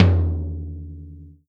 Tom 11.wav